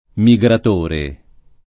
migratore [ mi g rat 1 re ]